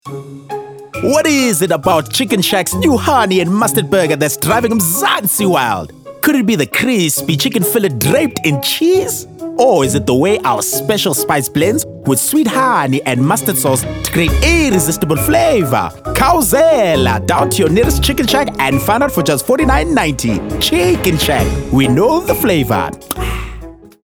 Male
South African English , American English , British English
Approachable, Authoritative, Bright, Bubbly, Character
My accent is South African but i can switch to American and British accent as well.
Microphone: Rode NT2A
Audio equipment: Vocal Isolation booth